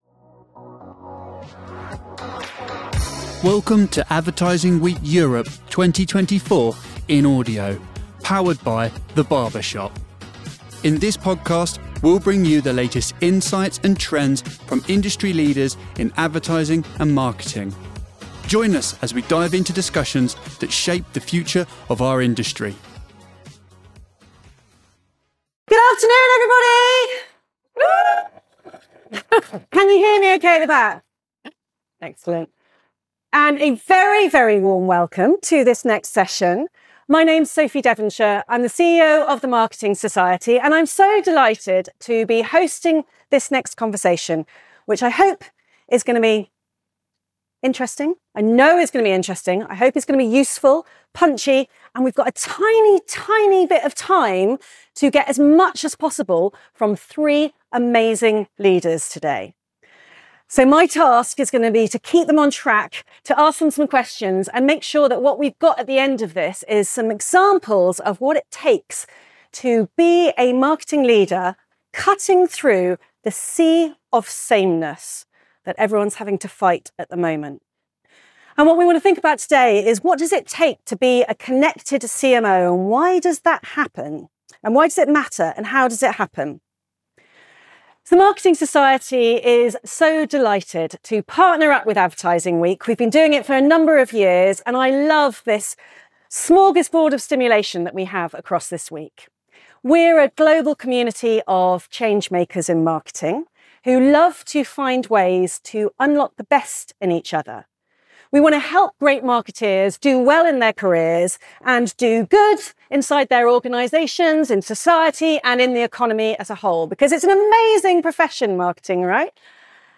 Discover how connectivity is reshaping the role of the CMO in this insightful session. Industry leaders discuss the importance of integrating digital strategies to create a unified brand experience. Learn how CMOs can bridge the gap between technology and customer engagement, leveraging data to drive personalised marketing efforts.